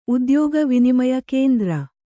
♪ udyōga vinimaya kēndra